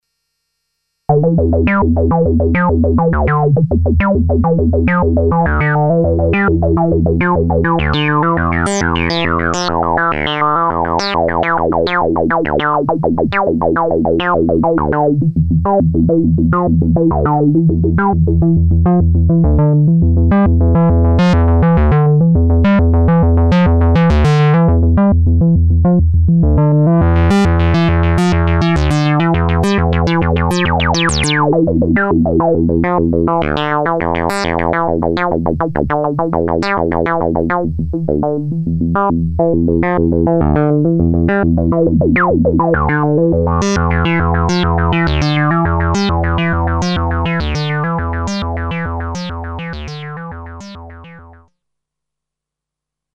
PWM - only VCO3 with square wave
Tweaking PWM, Cutoff, Decay and Emphasis,
using other sequencer to show velocity.